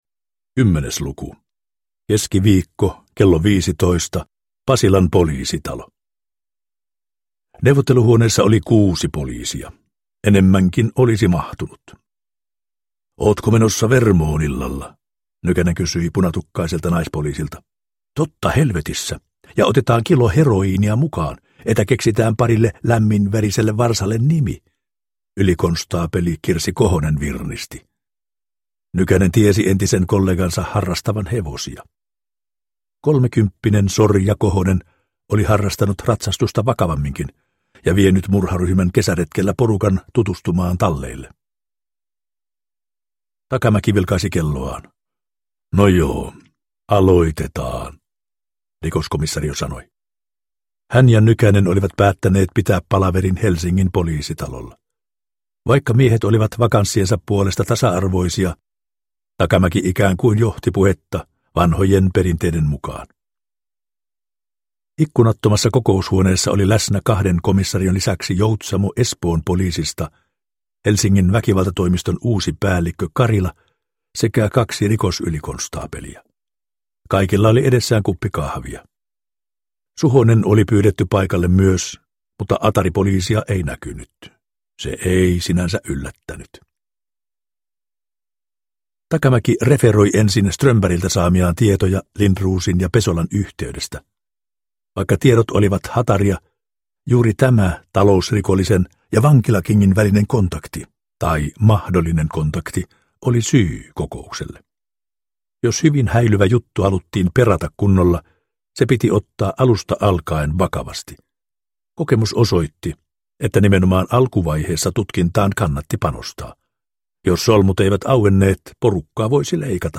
Tappokäsky – Ljudbok – Laddas ner